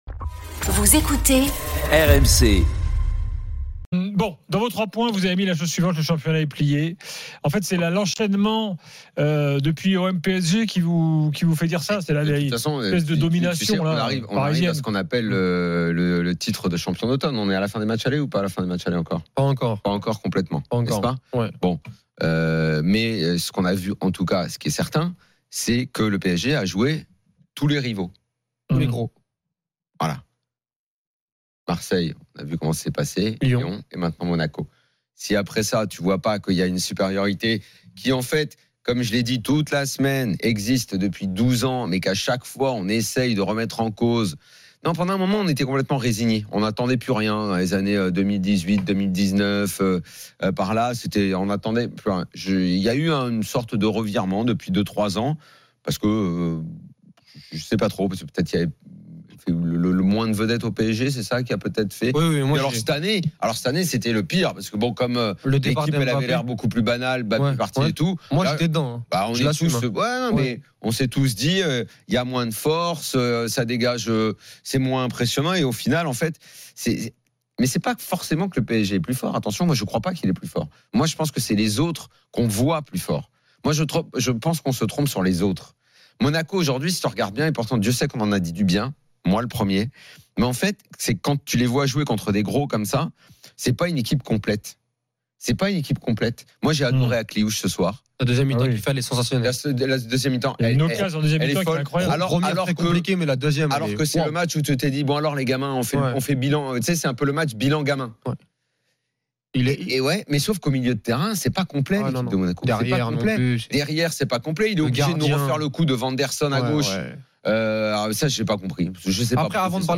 L’After foot, c’est LE show d’après-match et surtout la référence des fans de football depuis 19 ans ! Les rencontres se prolongent tous les soirs avec Gilbert Brisbois, Daniel Riolo et Florent Gautreau avec les réactions des joueurs et entraîneurs, les conférences de presse d’après-match et les débats animés entre supporters, experts de l’After et auditeurs RMC.